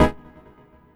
17CHORD01 -R.wav